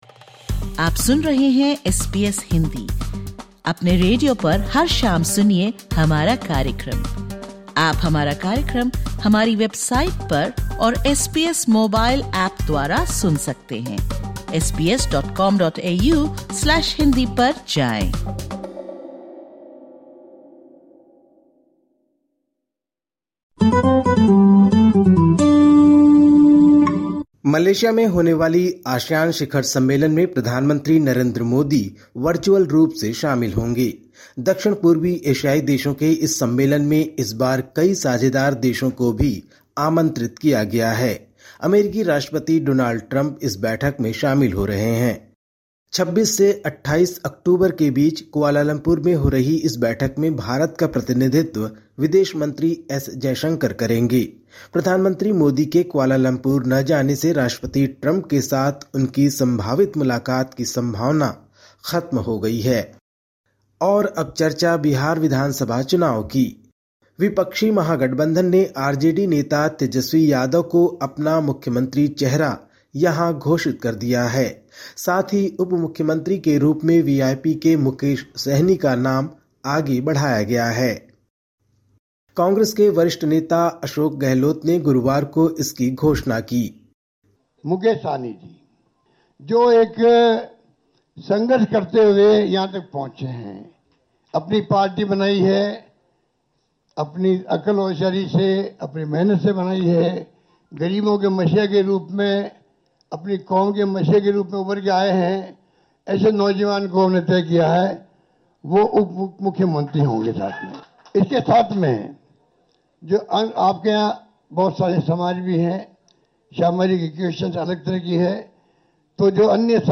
Listen to the latest SBS Hindi news from India. 24/10/2025